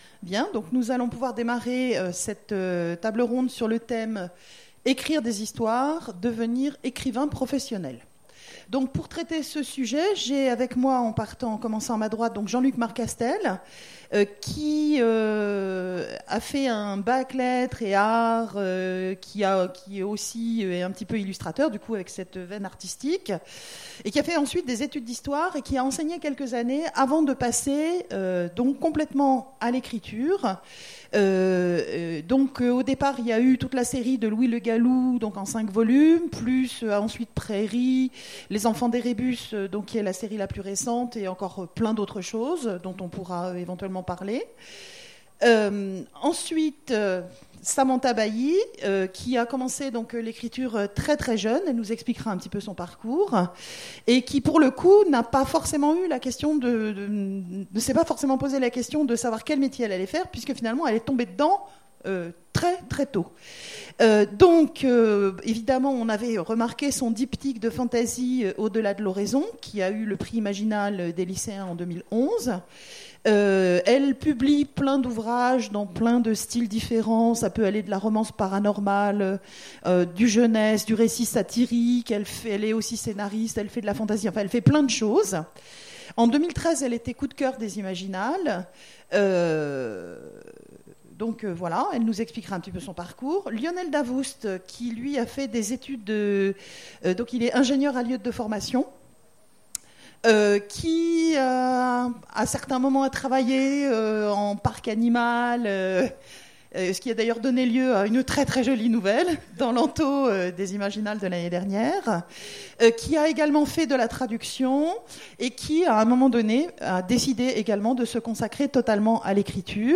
Imaginales 2016 : Conférence Écrire des histoires...